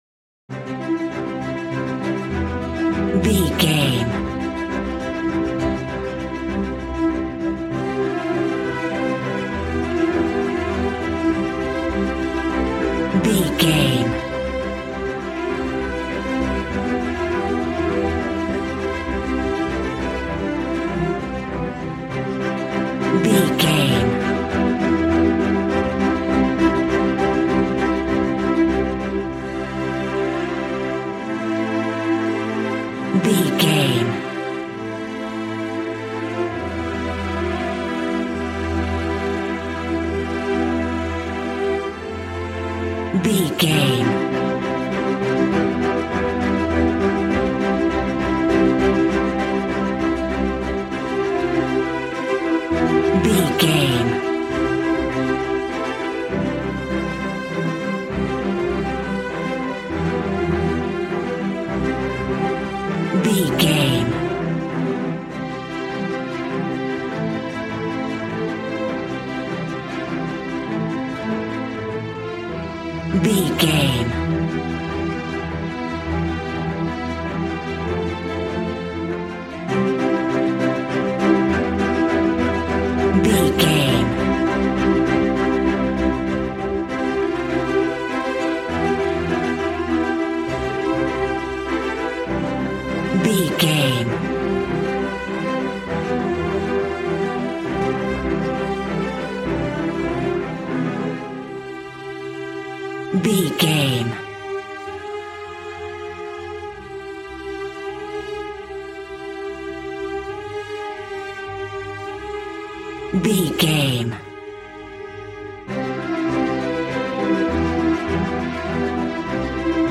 Aeolian/Minor
regal
strings
brass